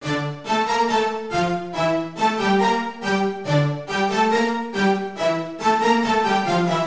陷阱弦乐
描述：很好的陷阱歌曲的前奏，但它也可以用于dubstep。我在Sylenth1 VST中制作的。调性：d小调。和弦行进：DGGA，玩得开心！;)
标签： 140 bpm Trap Loops Strings Loops 1.16 MB wav Key : D